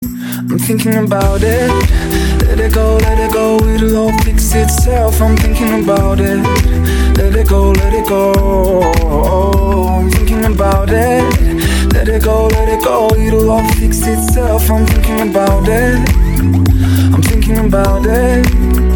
мужской вокал
мелодичные
dance
спокойные
club